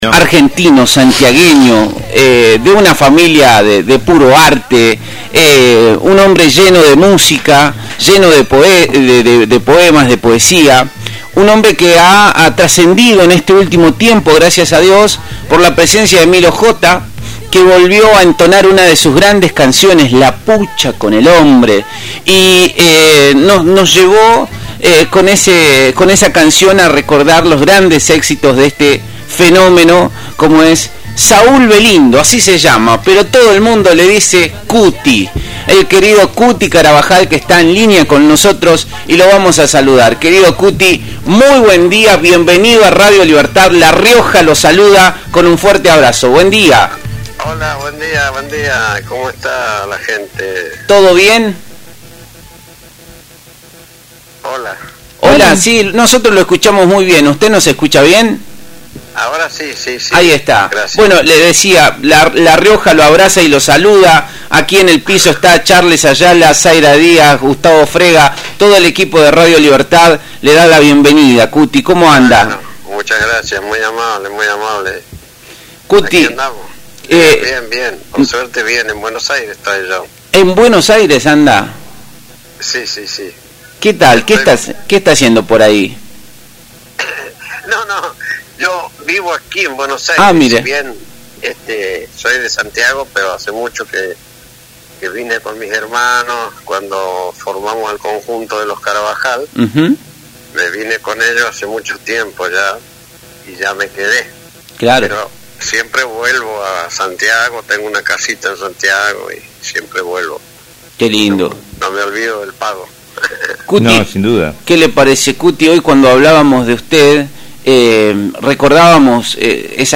En una charla telefónica íntima y llena de memoria, Cuti Carabajal —referente indiscutido del folclore argentino— compartió con Radio Libertad, en el programa Ecos Sociales, los orígenes de su carrera musical y la historia detrás de una de sus canciones más emblemáticas: La Pucha con el Hombre.